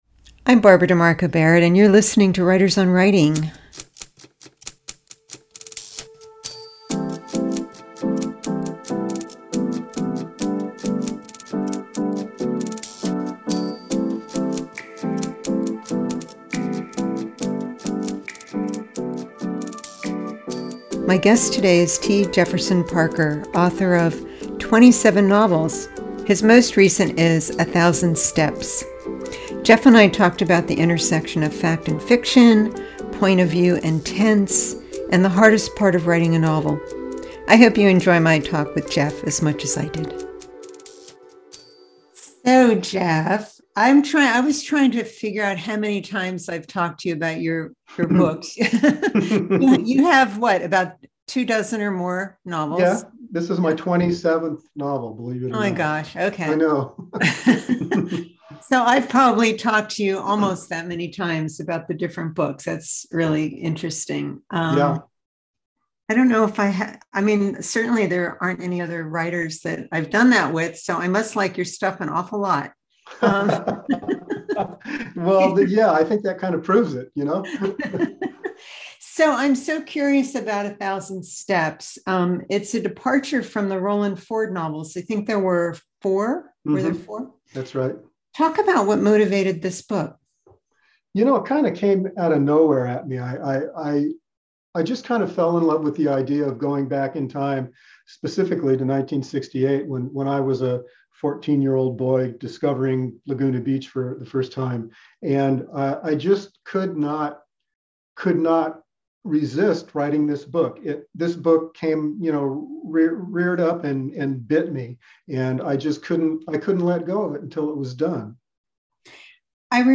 I wanted to hear her voice again and wanted to share this interview with our listeners.